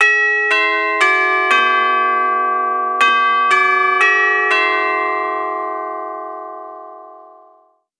Seks ulike ringetoner
3-Westminster.wav